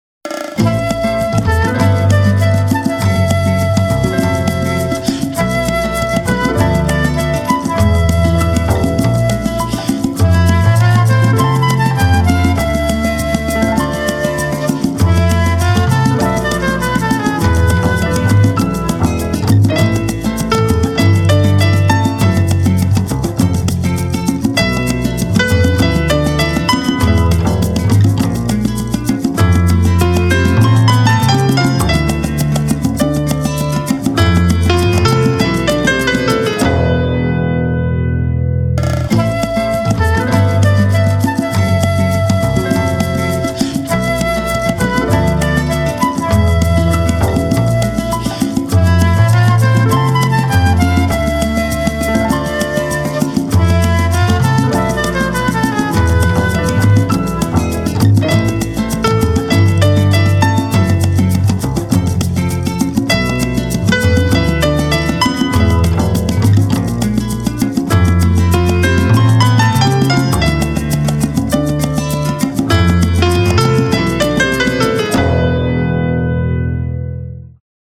• Качество: 320, Stereo
инструментальные